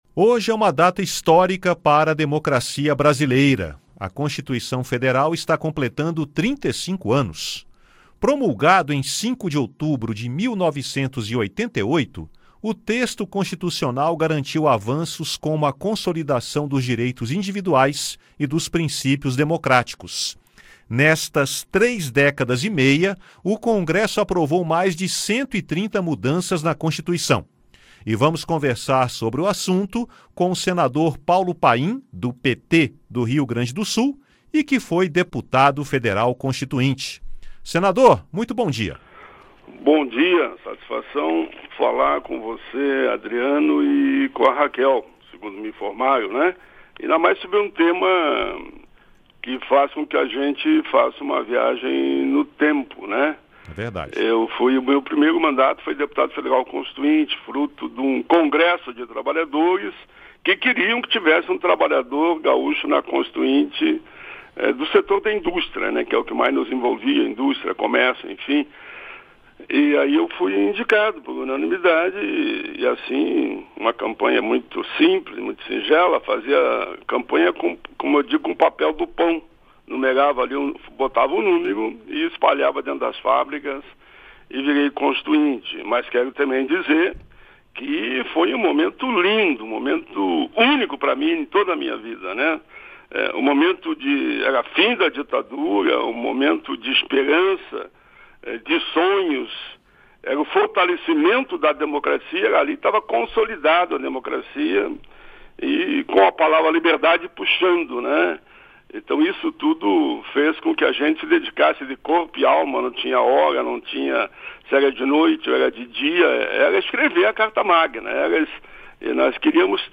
O senador Paulo Paim (PT-RS), que foi deputado federal constituinte, comenta essa data histórica para a democracia, a consolidação dos direitos fundamentais e dos princípios democráticos.